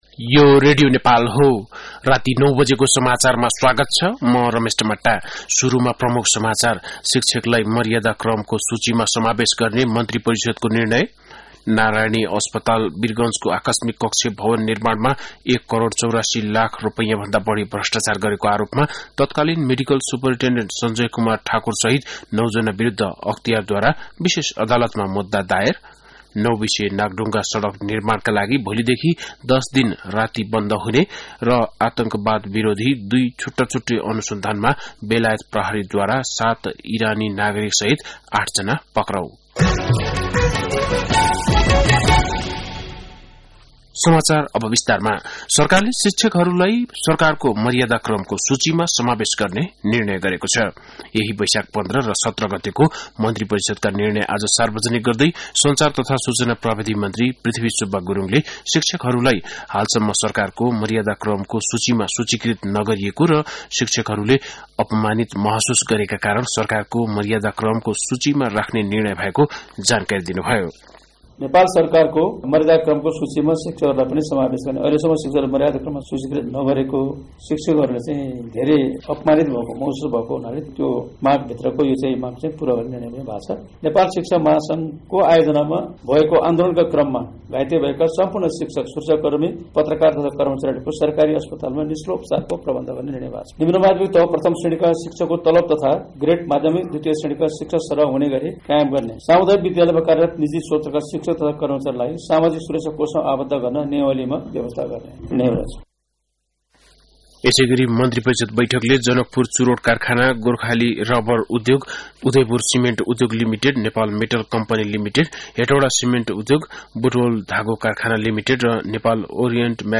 बेलुकी ९ बजेको नेपाली समाचार : २१ वैशाख , २०८२
9-pm-nepali-news.mp3